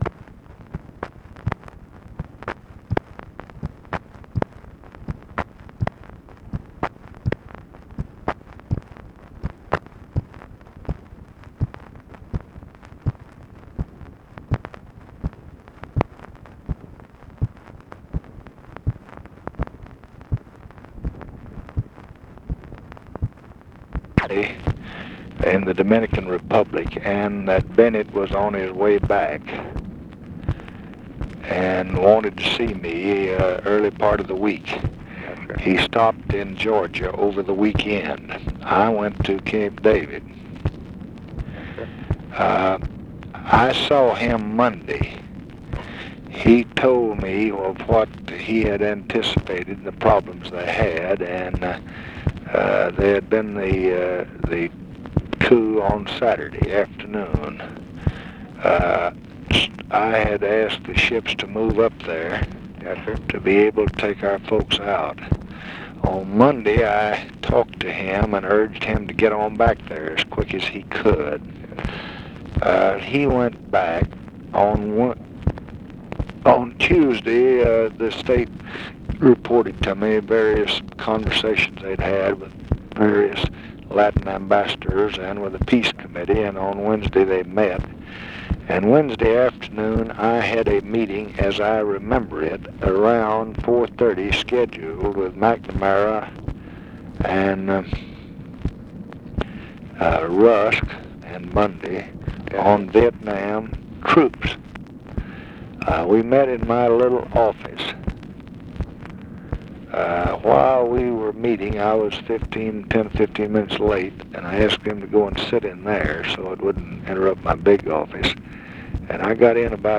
Conversation with WILLIAM RABORN, May 12, 1965
Secret White House Tapes